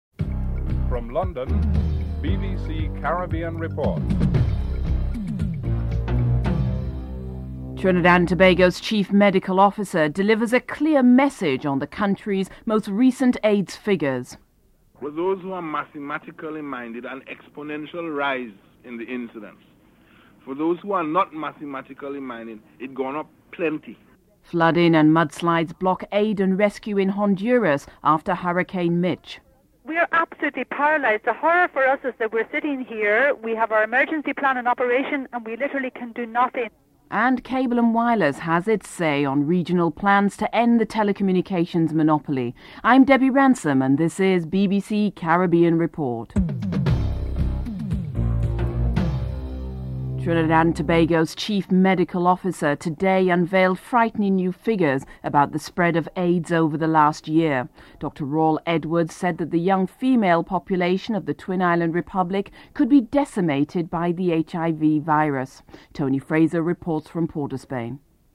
1. Headlines (00:00-00:49)